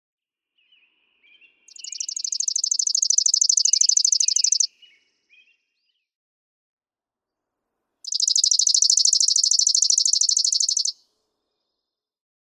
Chipping sparrow
Garland Lodge and Golf Resort, Lewiston, Michigan.
♫190—one song from each of two neighboring males
190_Chipping_Sparrow.mp3